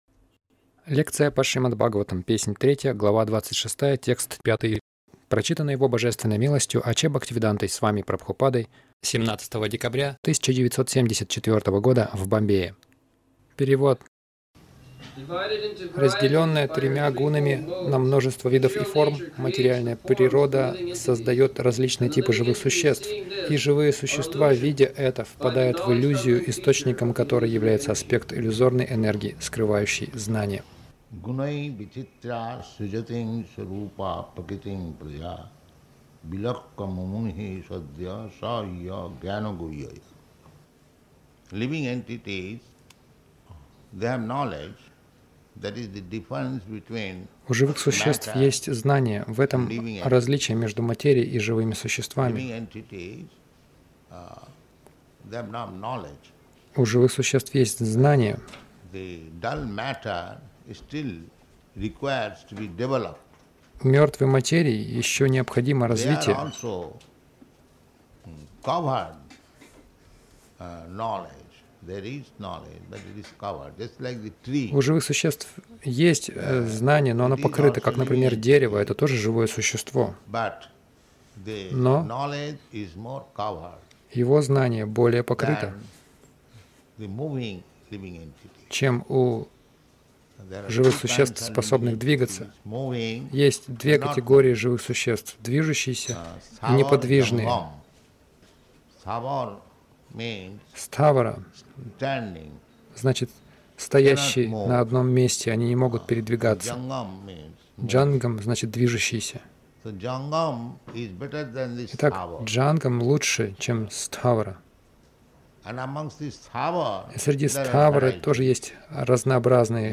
Милость Прабхупады Аудиолекции и книги 17.12.1974 Шримад Бхагаватам | Бомбей ШБ 03.26.05 — Служите не себе, а Кришне Загрузка...